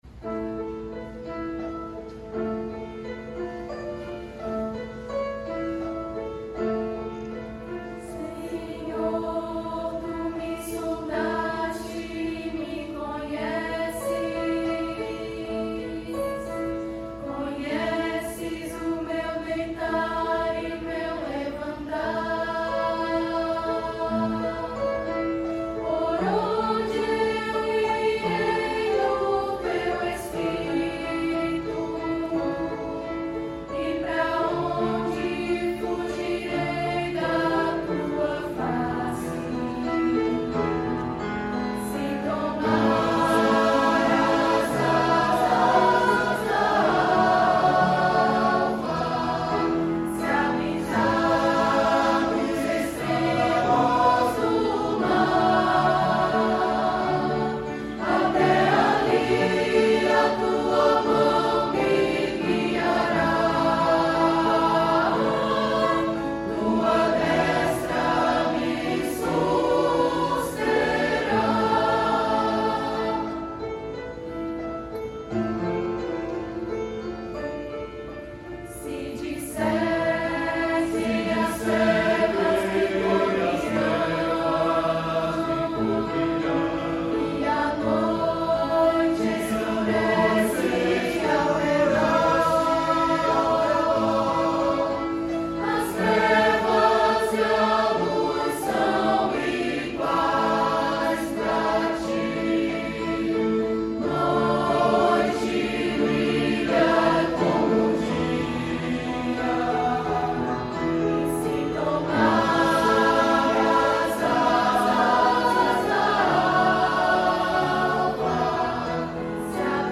“Asas da Alva” - Coral IBLM
Igreja Batista Luz do Mundo, Fortaleza/CE.